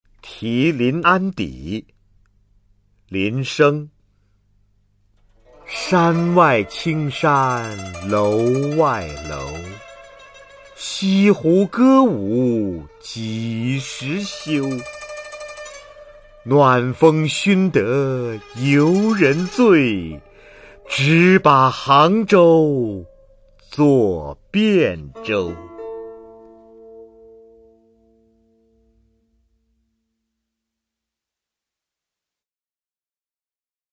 [宋代诗词朗诵]林升-题临安邸（男） 古诗词诵读